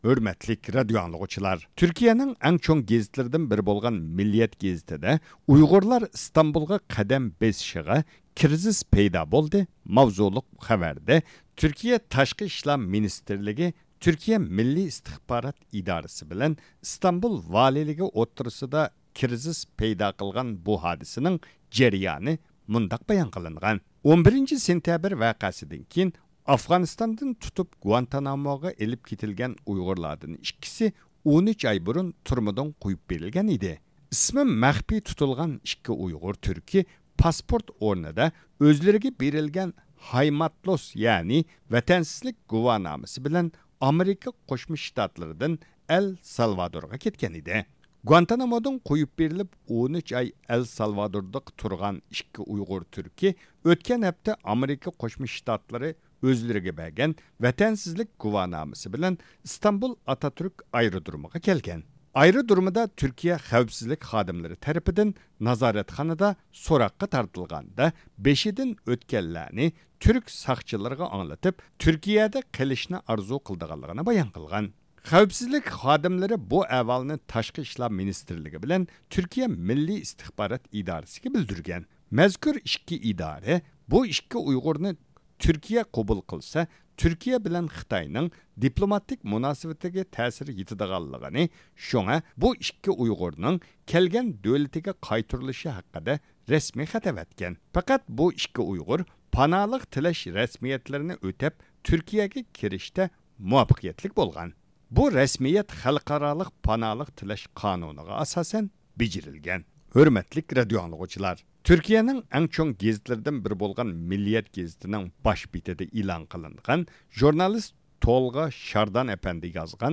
بۇ قېتىم قوبۇل قىلىشىدىكى سەۋەب نېمە؟ بۇنىڭ تۈركىيە-خىتاي مۇناسىۋىتىگە قانداق تەسىرى بولار؟ دېگەنگە ئوخشاش سوئاللارغا جاۋاب تېپىش ئۈچۈن مۇناسىۋەتلىك مۇتەخەسسىسلەرگە مىكروفونىمىزنى ئۇزاتتۇق.